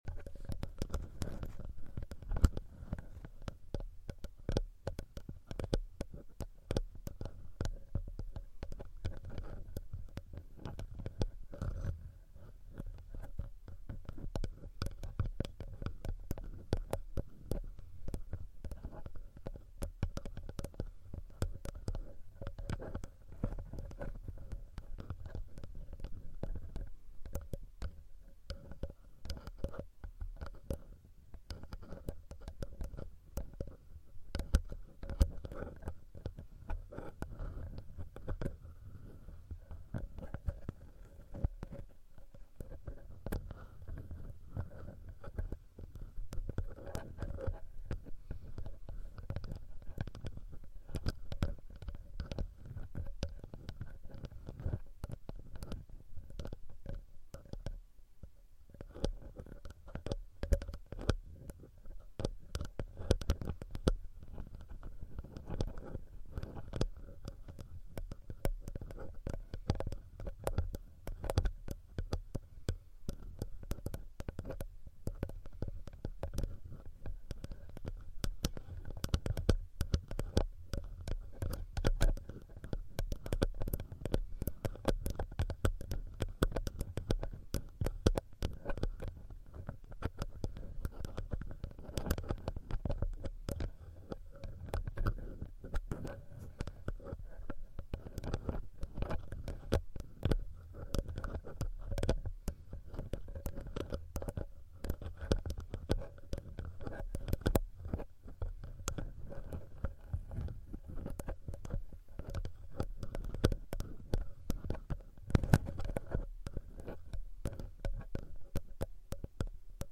10 Minutes Tascam Tapping And Sound Effects Free Download